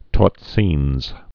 (tôtsēns)